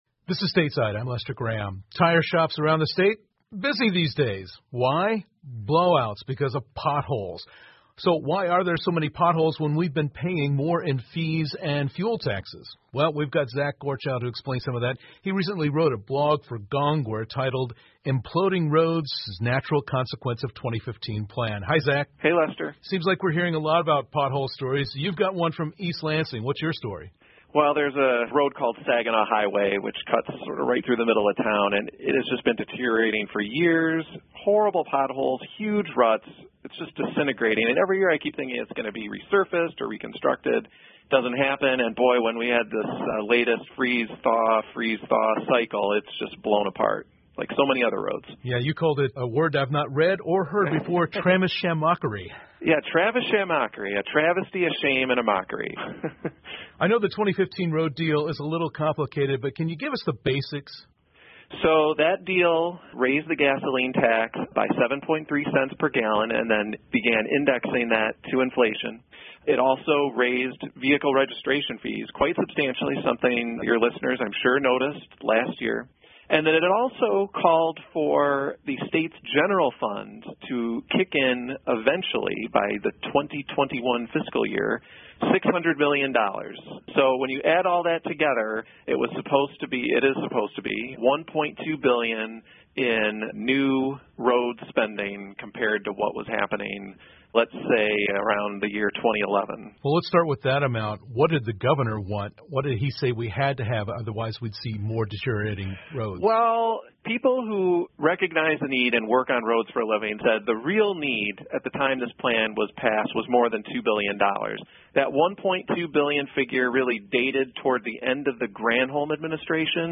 密歇根新闻广播 费用和燃油税增加,为什么路上还有那么多坑 听力文件下载—在线英语听力室